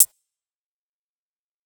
HiHat (35).wav